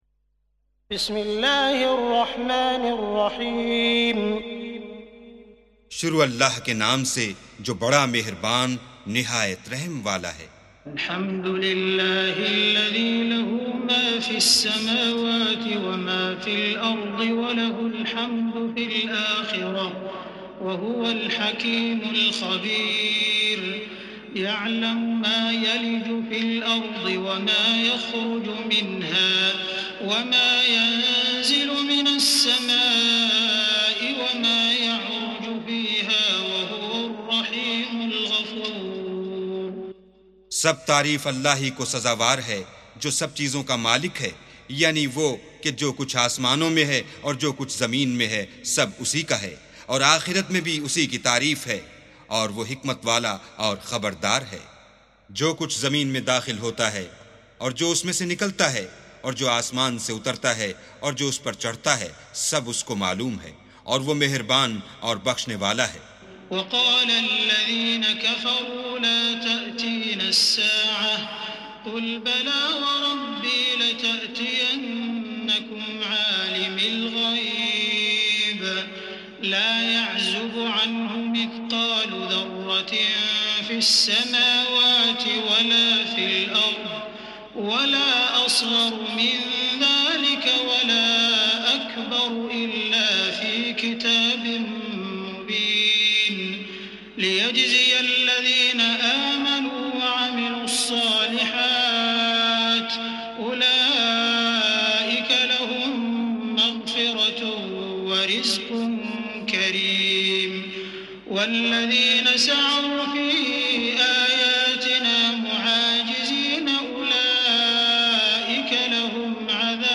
سُورَةُ سَبَإٍ بصوت الشيخ السديس والشريم مترجم إلى الاردو